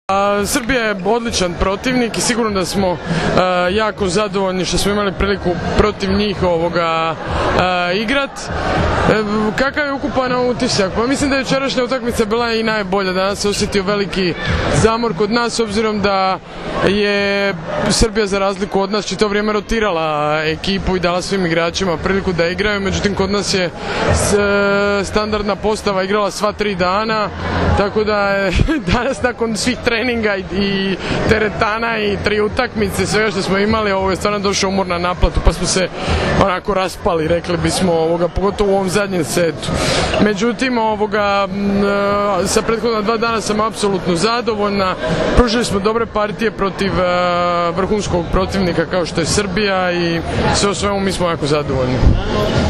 IZJAVA MAJE POLJAK